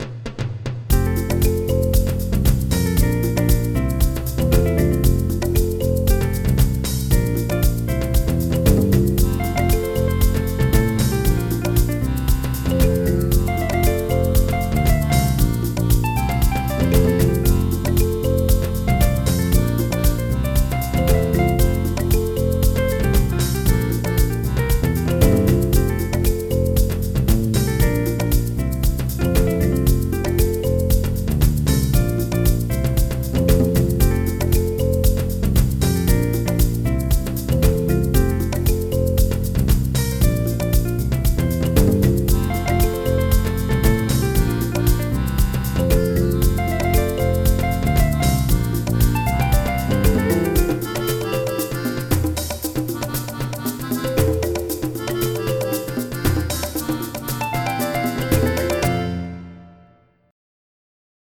MIDI Music File
african6.mp3